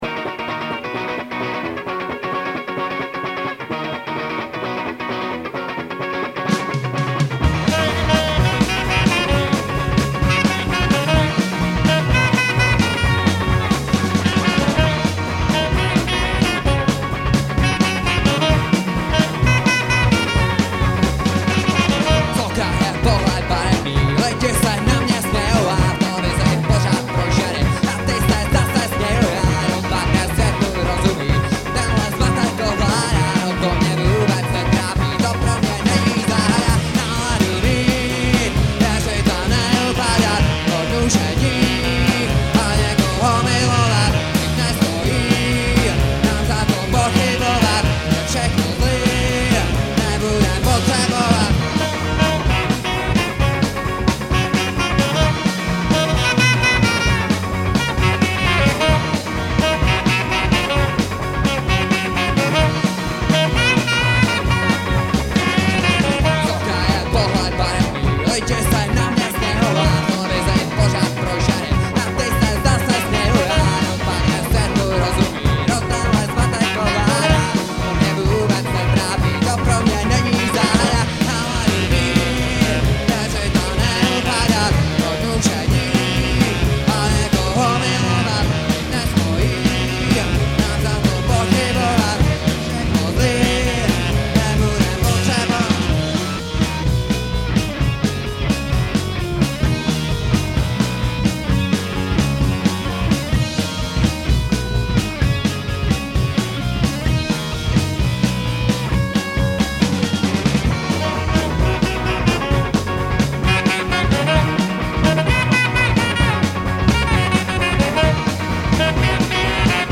Nahráno živě na koncertu v klubu Prosek dne 1.3.2001